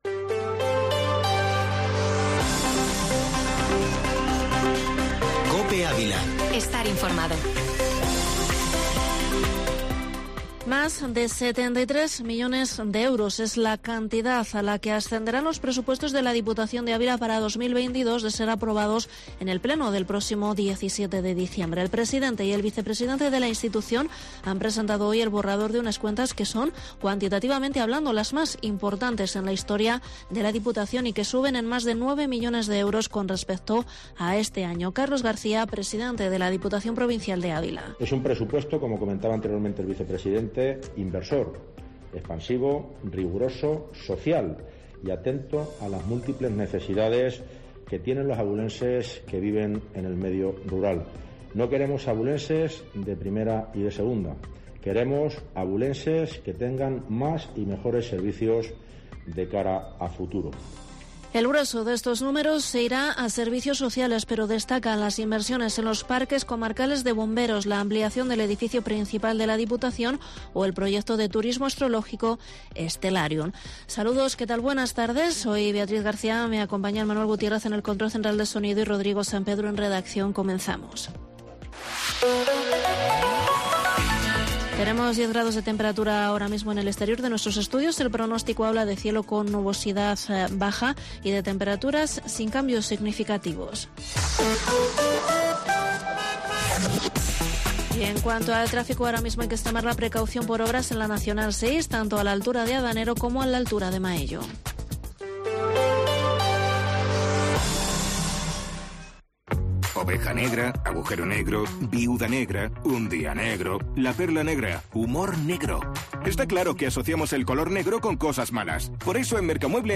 Informativo Mediodía Cope en Avila 17/11/2021